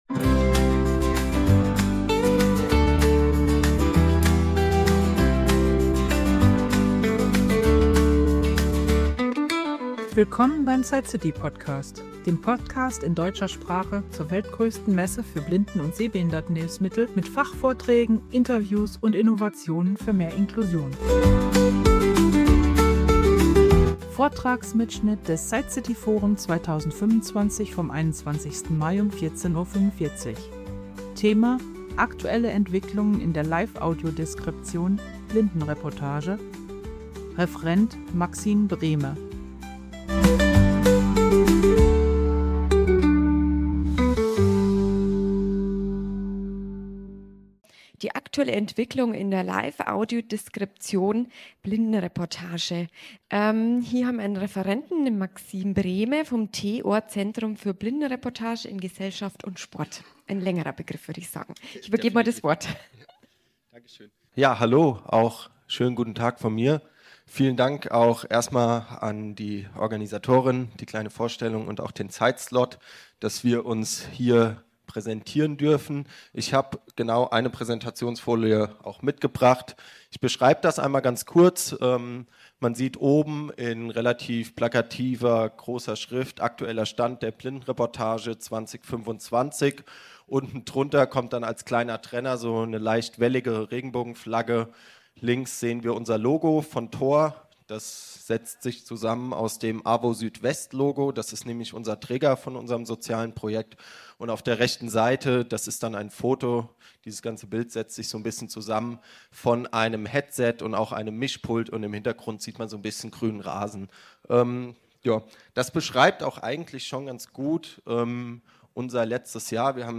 Beschreibung vor 4 Monaten Vortrags-Mitschnitt aus dem SightCity Forum 2025 vom 21.05.2025 um 14:45 Uhr.